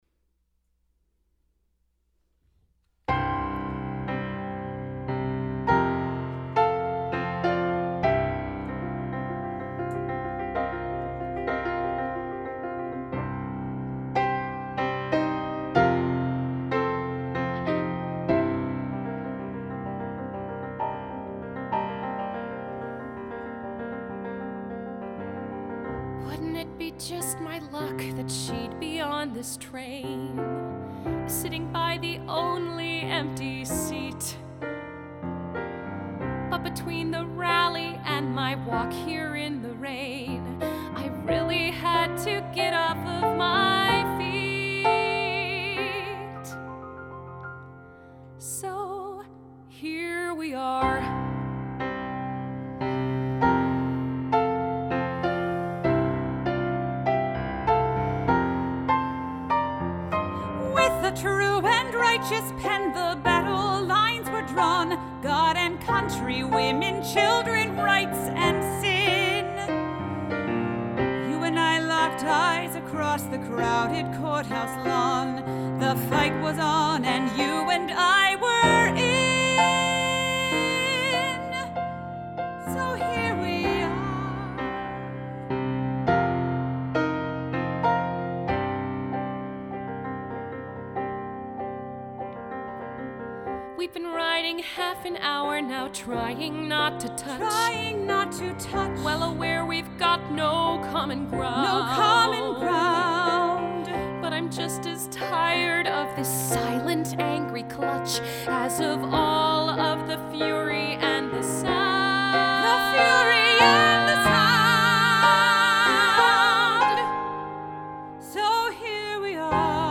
Low voice, high voice piano